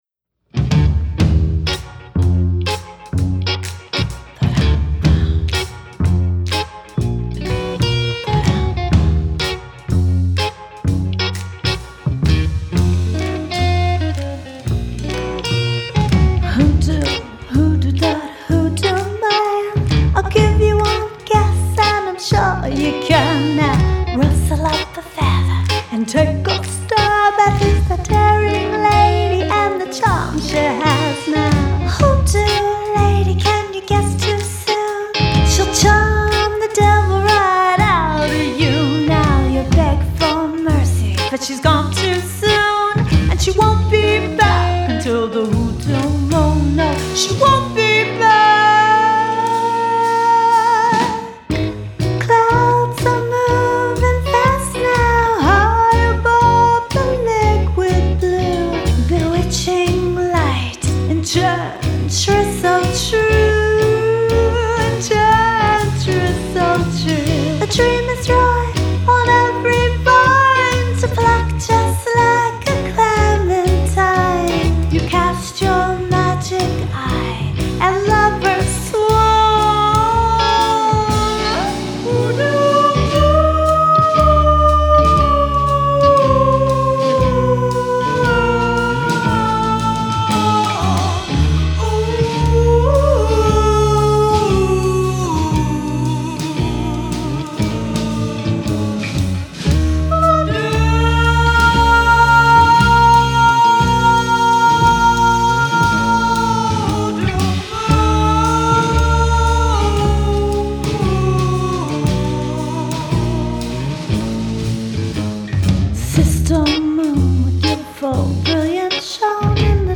Mystical Moonlight & True Love